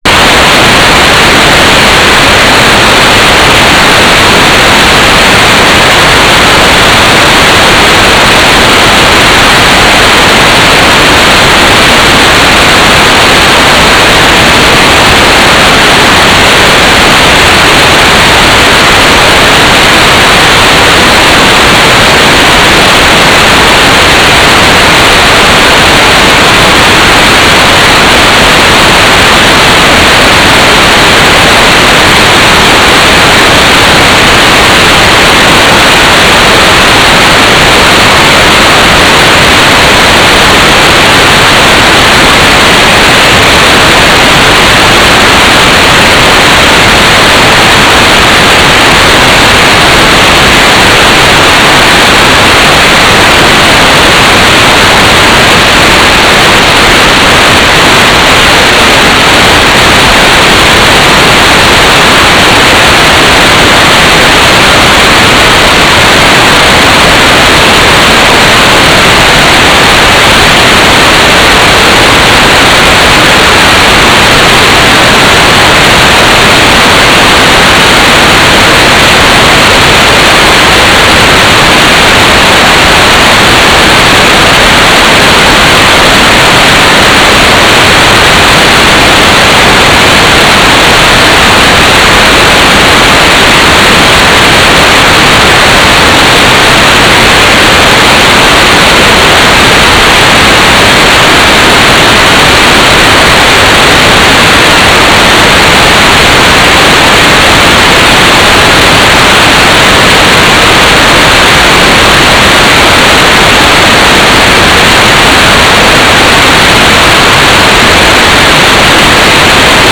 "waterfall_status": "without-signal",
"transmitter_description": "Mode U - GFSK4k8 - AX.25 - Telemetry",